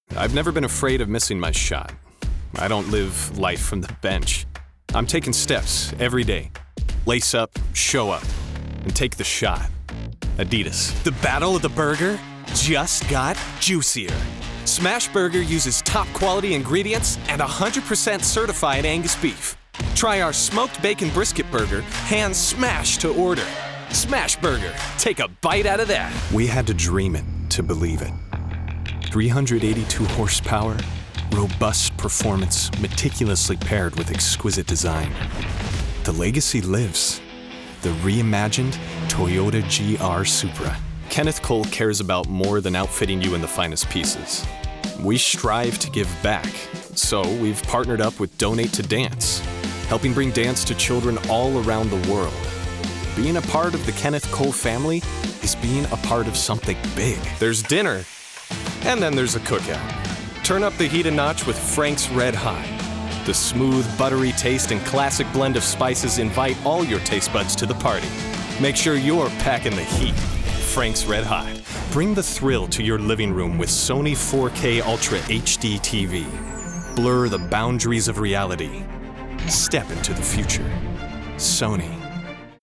Young Adult, Adult, Mature Adult
Location: Seattle, WA, USA Languages: english Accents: british rp | character british rp | natural cockney | character italian | character midwestern us | natural new york | character russian | character southern us | natural standard us | character standard us | natural texan us | natural Voice Filters: VOICEOVER GENRE COMMERCIAL 💸 GAMING 🎮